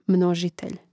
mnòžitelj množitelj